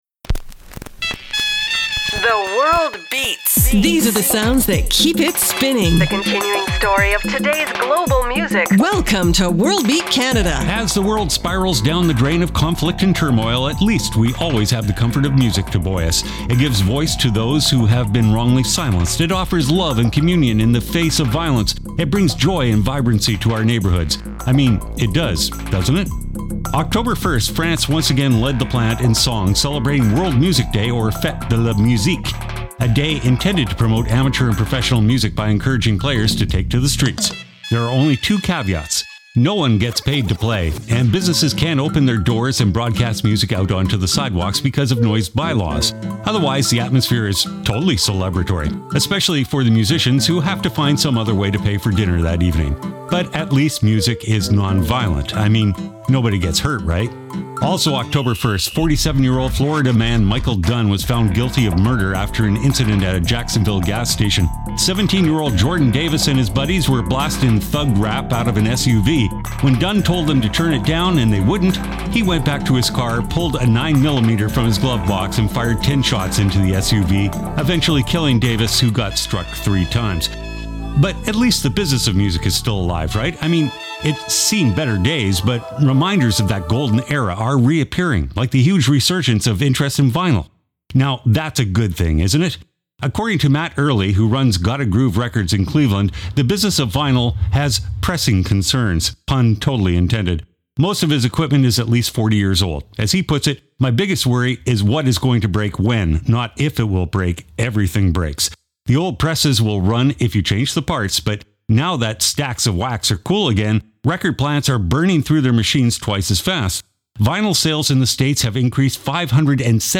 exciting global music alternative to jukebox radio
Spooky Moravian folk distortion